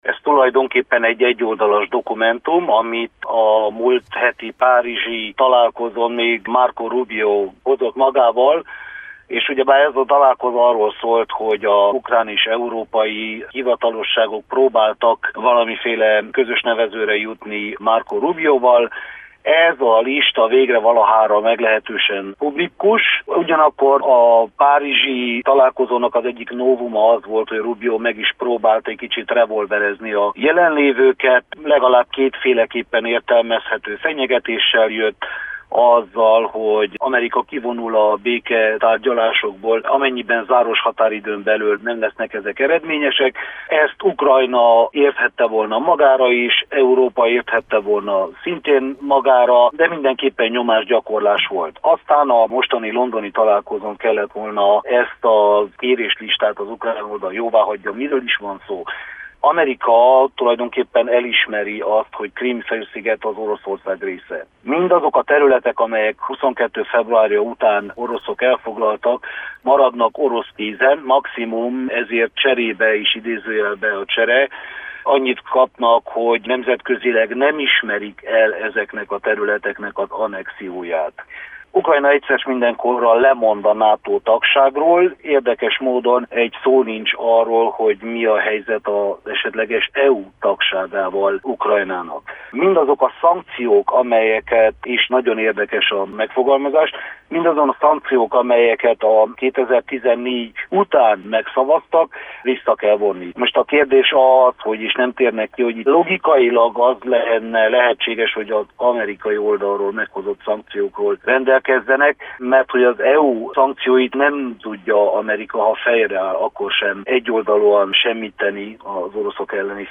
Mi volt a dokumentumban és kinek kedvezett volna? Külpolitikai szakértővel elemeztünk.